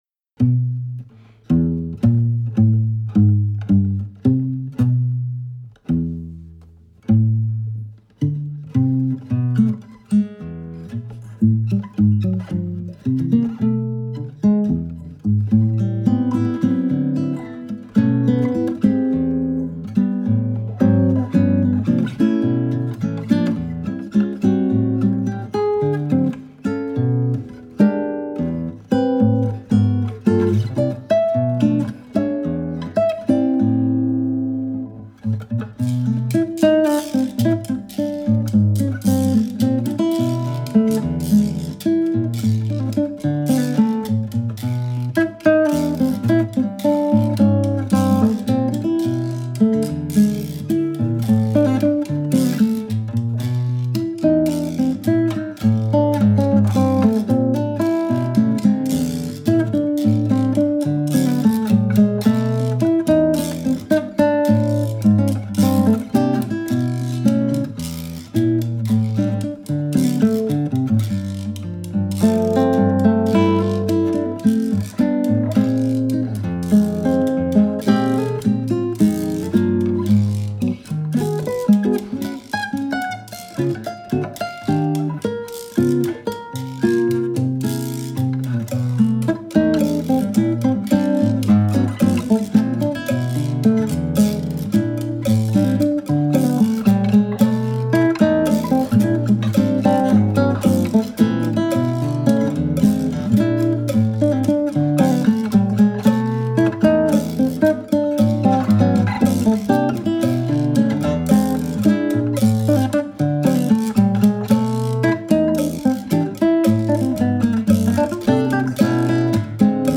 Tags: Violão Solo